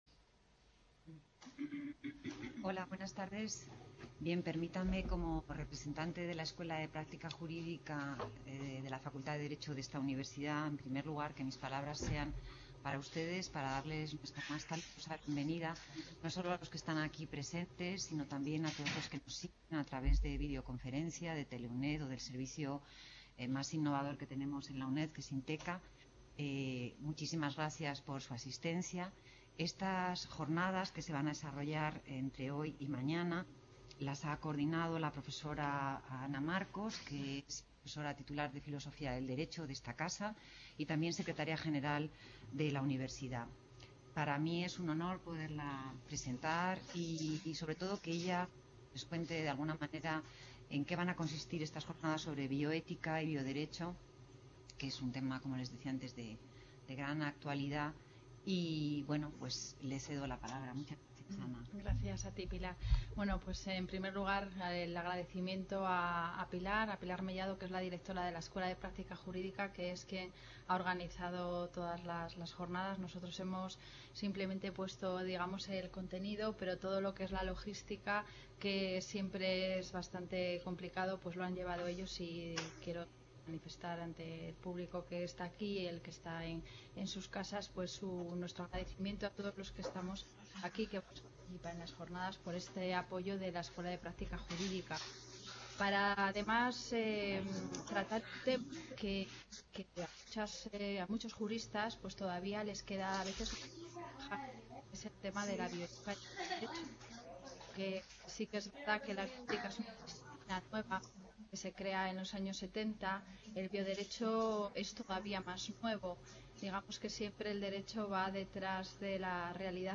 | Red: UNED | Centro: UNED | Asig: Reunion, debate, coloquio... | Tit: CONFERENCIAS | Autor:varios